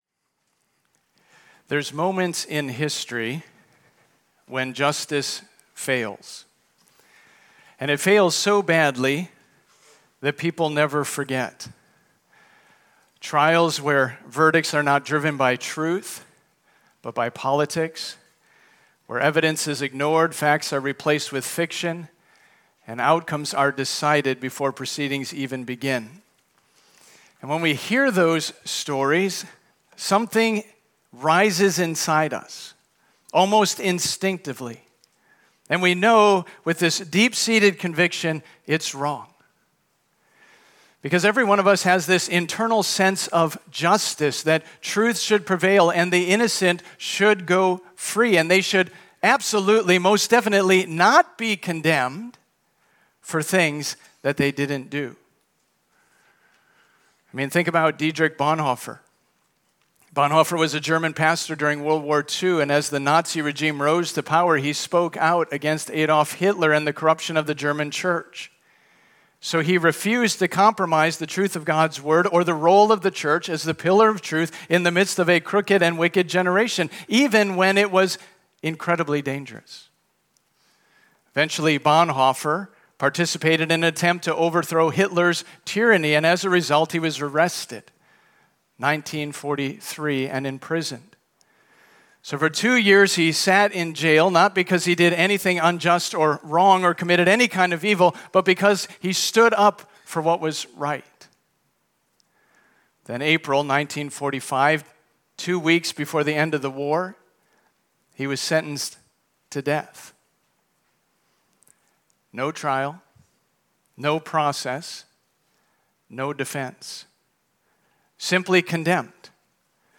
Sermons
Sermons from Christ Proclamation Church: Windsor, CT